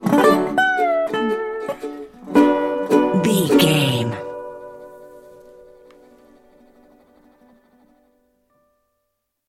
Uplifting
Ionian/Major
acoustic guitar
bass guitar
ukulele
slack key guitar